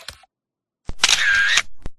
Free Foley sound effect: Keys Jingle.
Keys Jingle
Keys Jingle is a free foley sound effect available for download in MP3 format.
382_keys_jingle.mp3